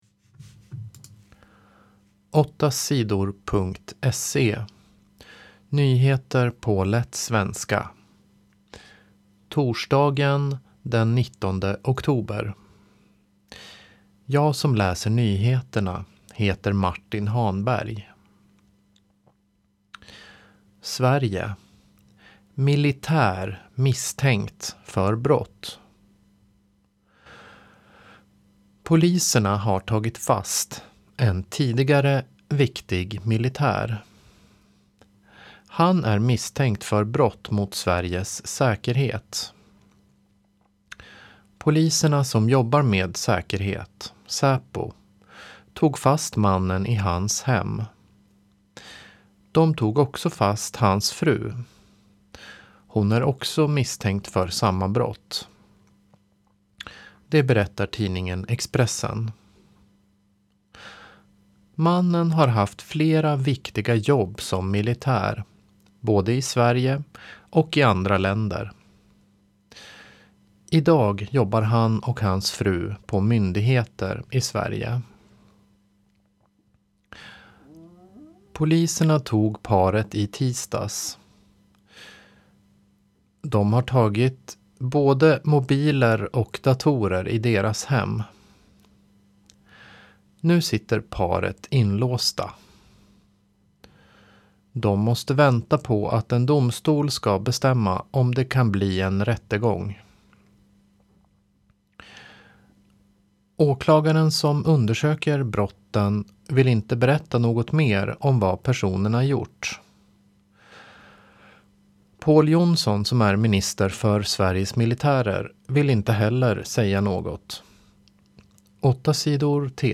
Nyheter på lätt svenska den 19 oktober